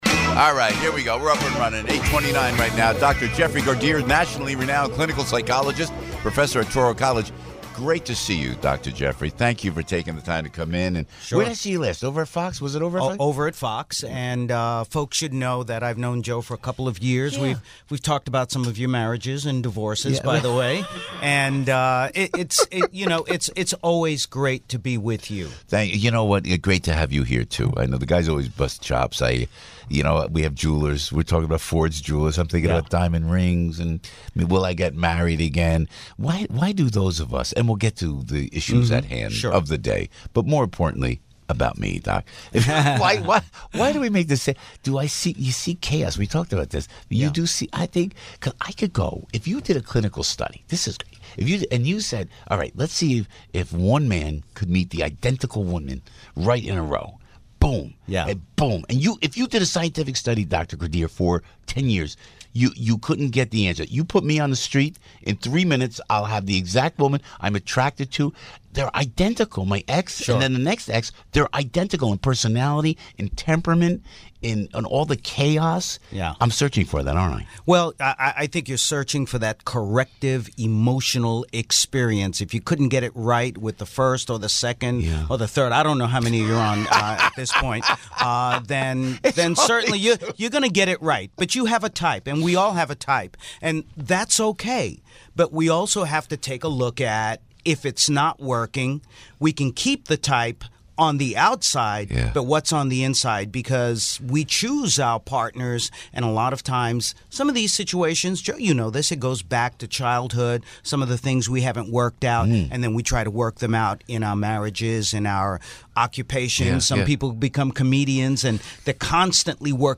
DR. JEFF GARDERE INTERVIEW – PISCOPO IN THE MORNING 10-31 | Dr. Jeff Gardere - America's Psychologist - Official Website